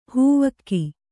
♪ hūvakki